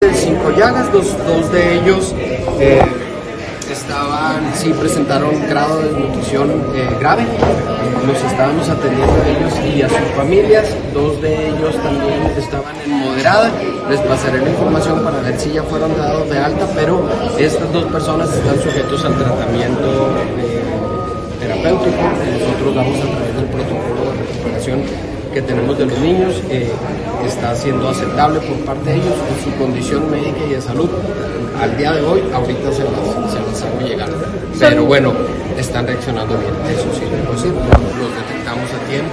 AUDIO: GILBERTO BAEZA MENDOZA, SECRETARIO DE SALUD (SS) ESTATAL